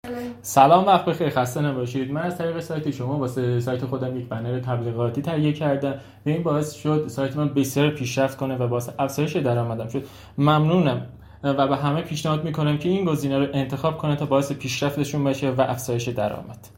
صدای مشتریان
صدای بخشی از مشتریان خوب سایت تبلیغات در گوگل